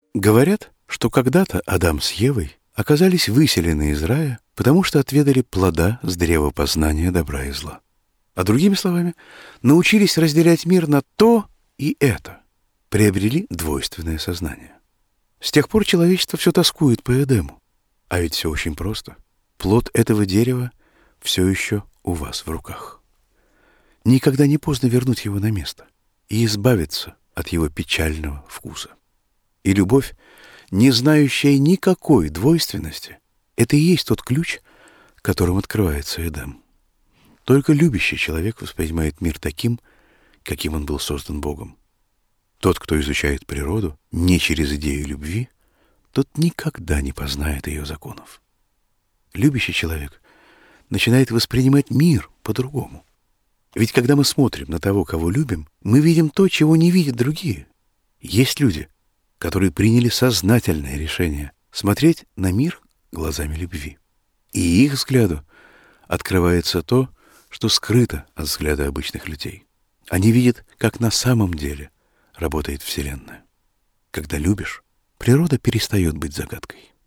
Фрагмент передачи Бориса Гребенщикова "Аэростат" на Радио России